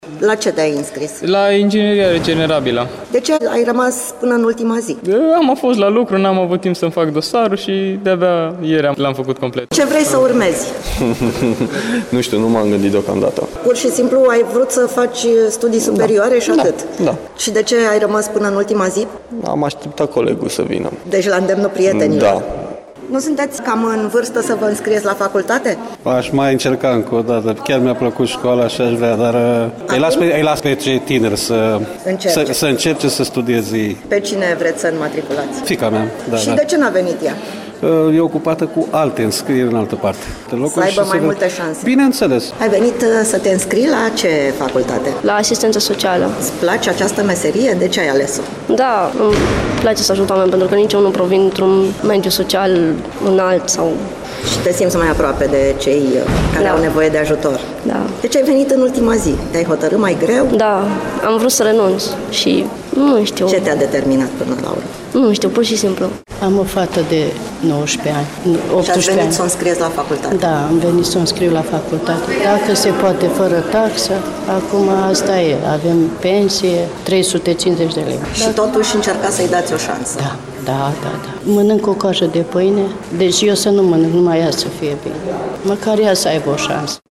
La Universitatea „Eftimie Murgu” din Reşiţa s-a încheiat ieri prima sesiune de înscrieri. Deoarece atmosfera a fost destul de animată pe holuri